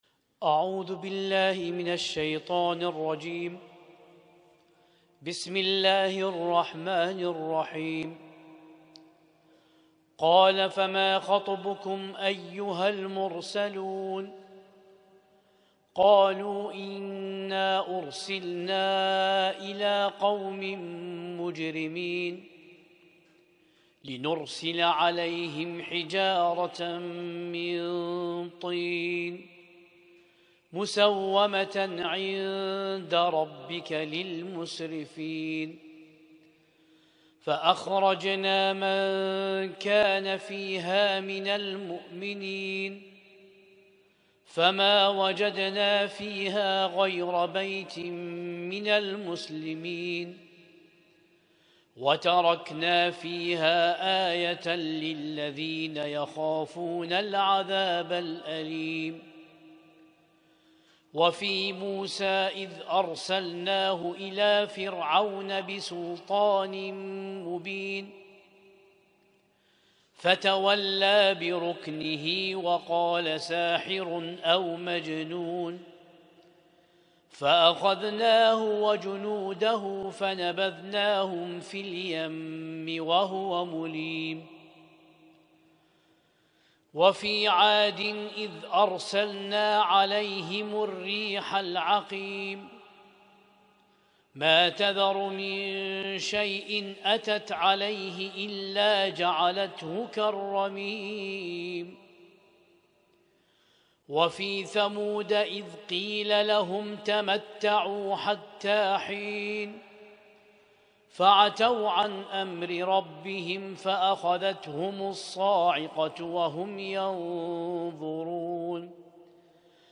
اسم التصنيف: المـكتبة الصــوتيه >> القرآن الكريم >> القرآن الكريم 1447